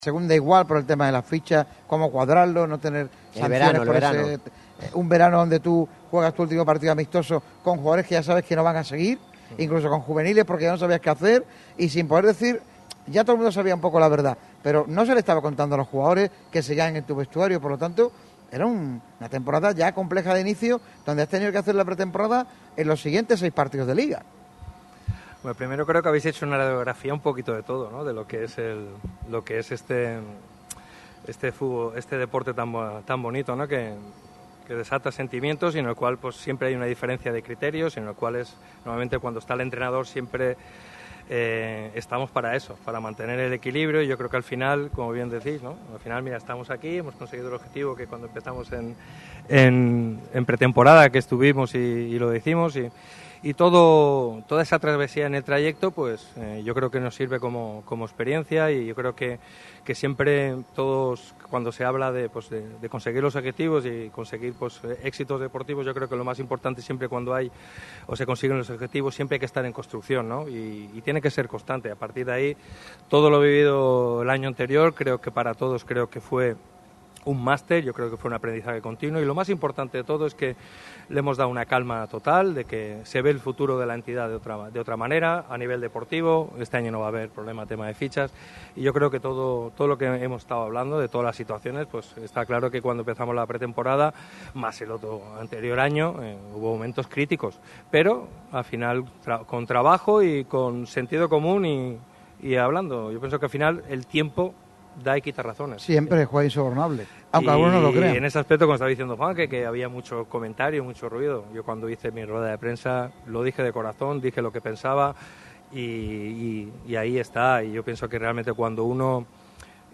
Tal es su meritocracia que Radio MARCA Málaga decidió entregarle el Escudo de Oro en el programa emitido desde la fábrica de Cervezas Victoria.